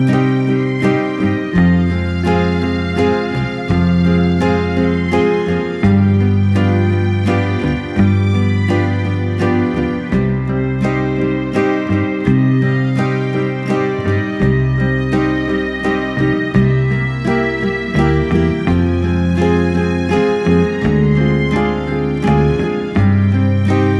no Backing Vocals Comedy/Novelty 3:11 Buy £1.50